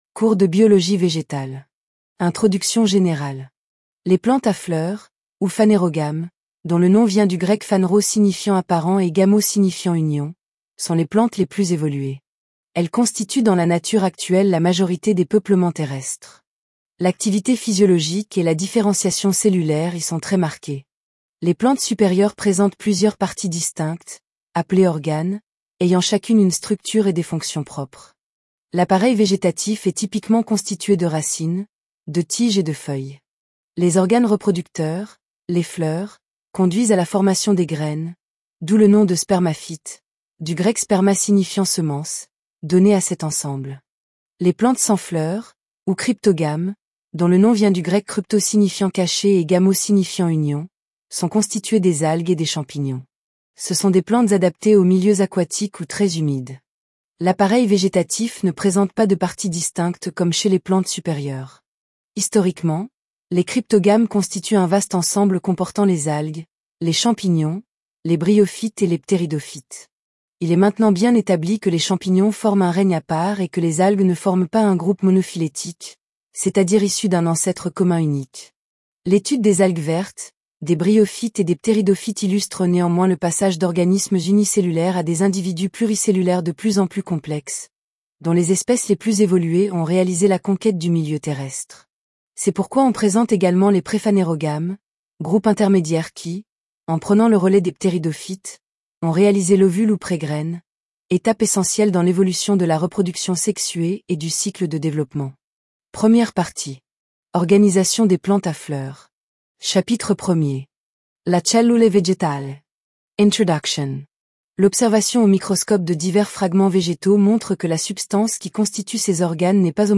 Biologie_Vegetale_TTS.mp3